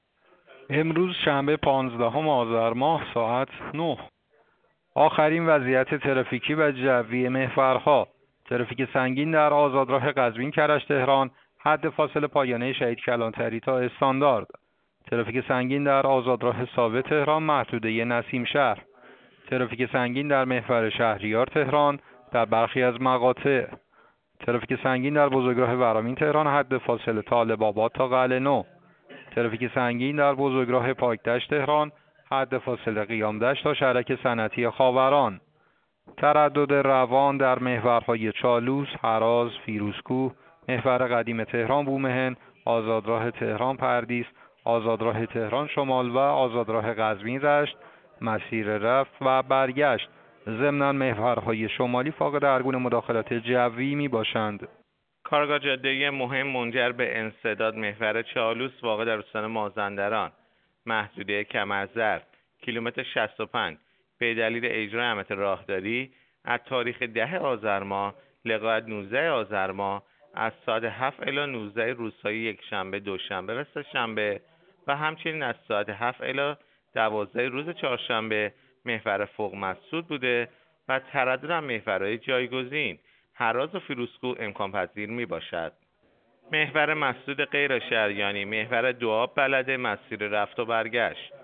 گزارش رادیو اینترنتی از آخرین وضعیت ترافیکی جاده‌ها ساعت ۹ پانزدهم آذر؛